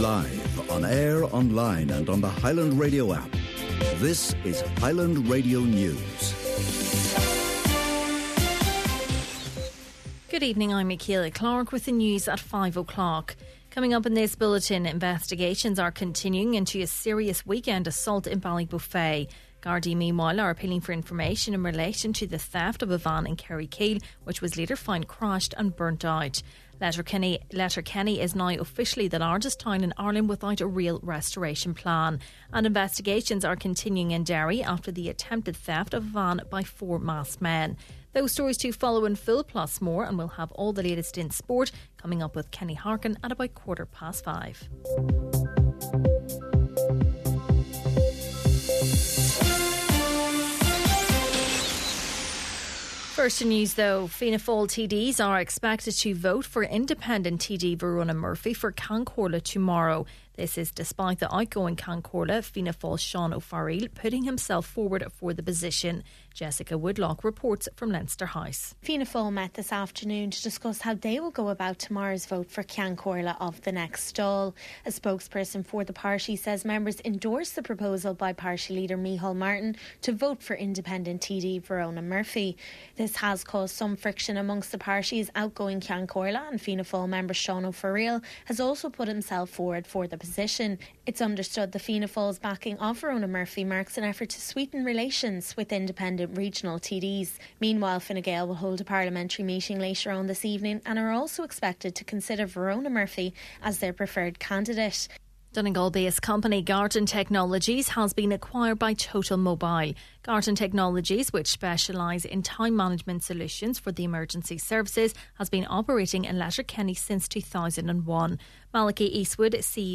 Main Evening News, Sport and Obituaries – Tuesday, December 17th